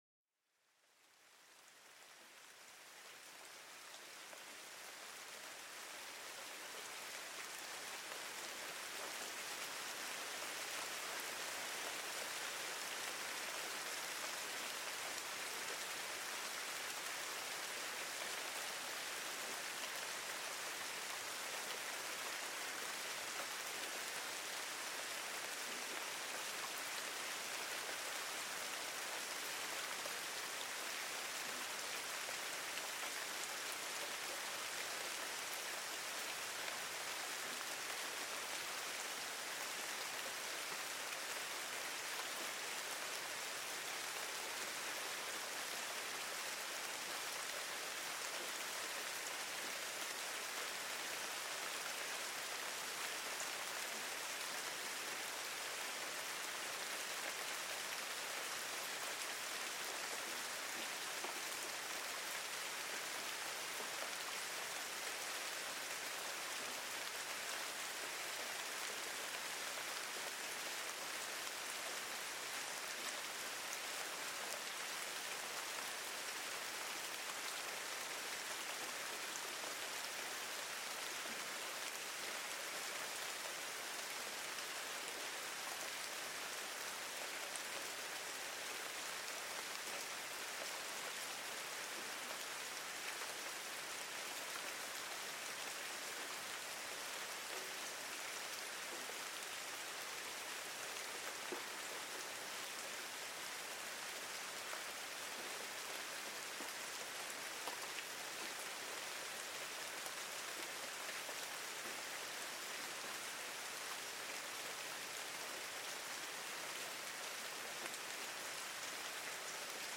Lluvia Calmante: Relajación y Bienestar al Sonido de las Gotas